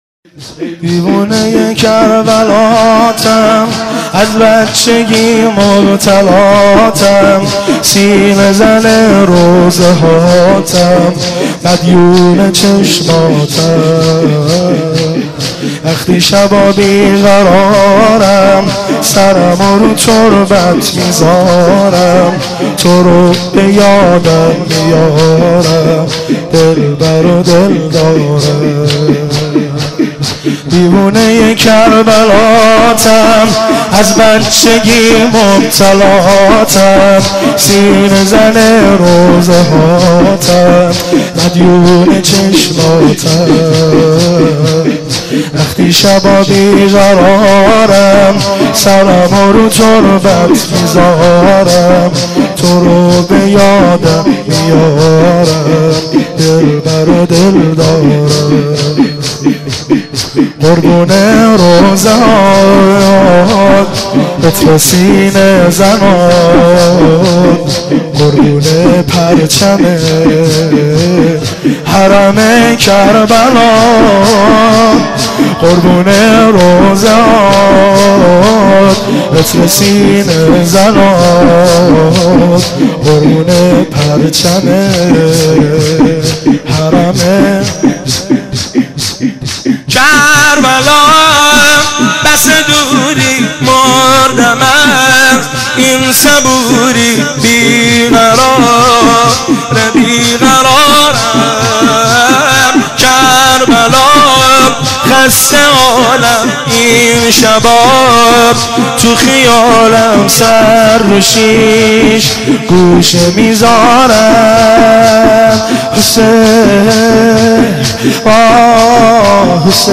شور شب اول فاطمیه 1388